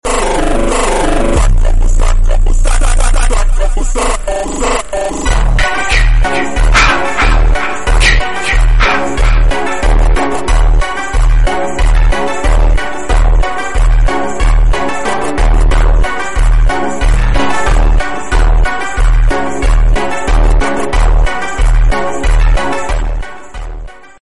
Super slowed